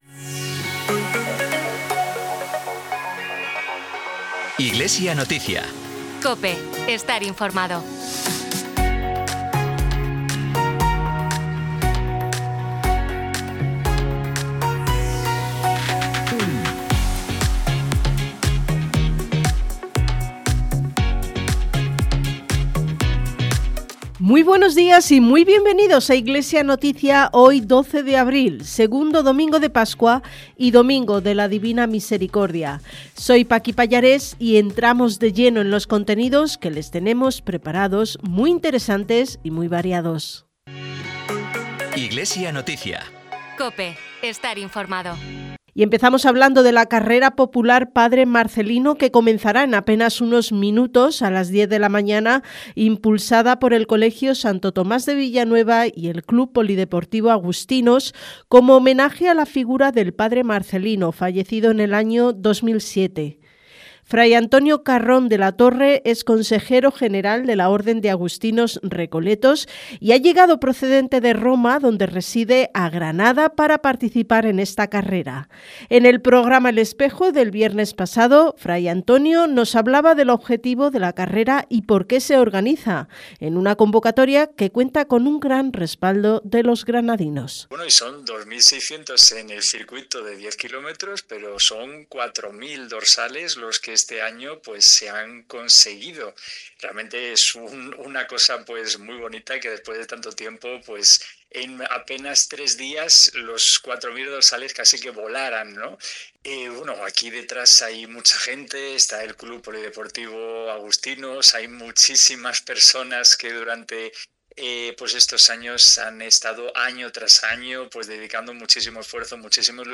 Programa emitido en COPE Granada y COPE Motril el 12 de abril de 2026.
Disponible el informativo diocesano “Iglesia Noticia” emitido el domingo día 12, II Domingo de Pascua y de la Divina Misericordia, en COPE Granada y COPE Motril.